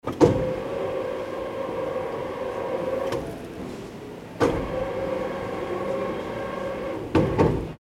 Gemafreie Sounds: Industrie
mf_SE-7579-conveyor_belt_2.mp3